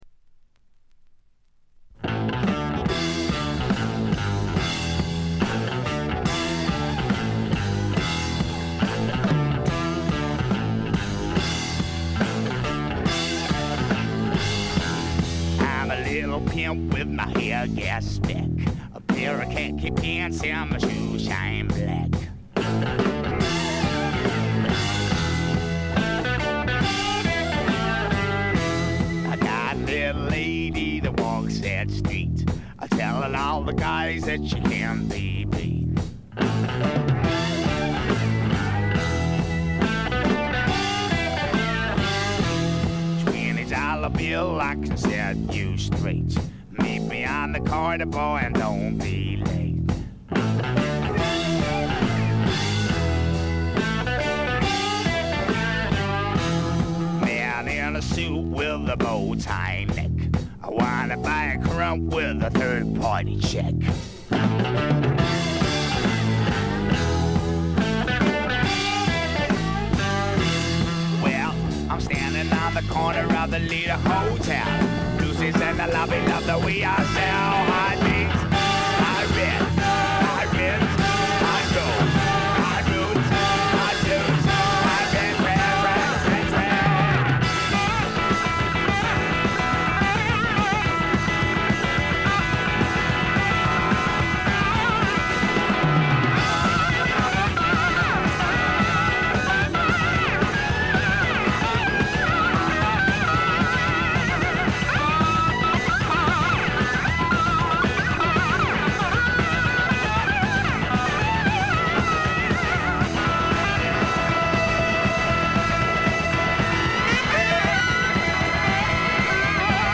他はチリプチや散発的なプツ音は出るもののまずまず。
へヴィーな英国流ブルースロックをやらせたら天下一品。
試聴曲は現品からの取り込み音源です。